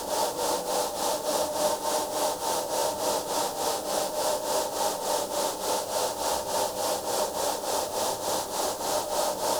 STK_MovingNoiseC-100_02.wav